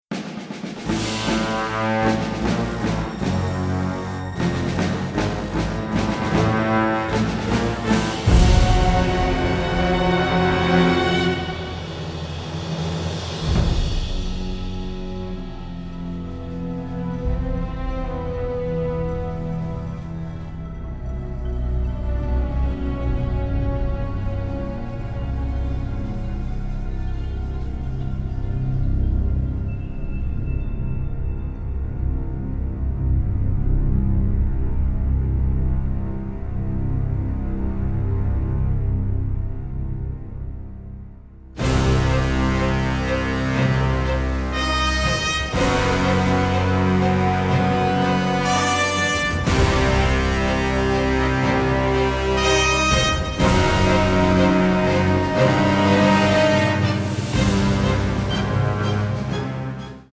dynamic score, written in a symphonic jazz style
Recorded in London